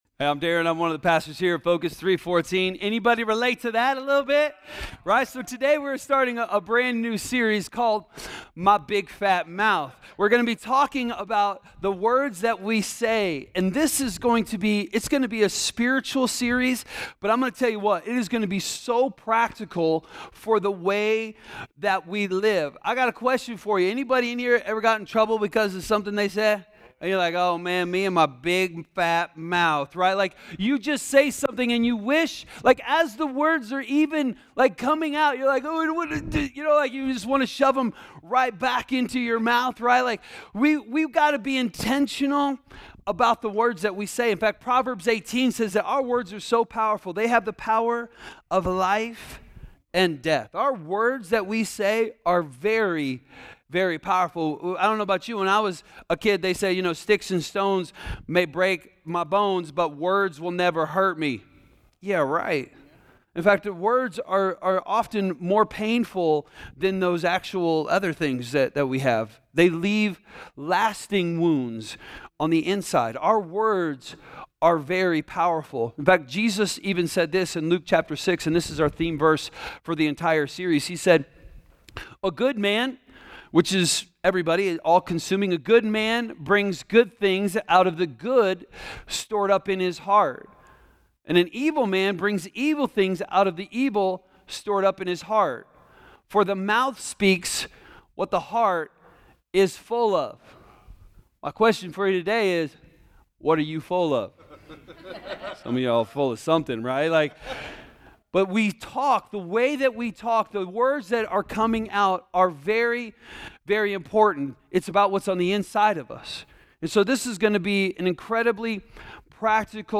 A message from the series "My Big Fat Mouth."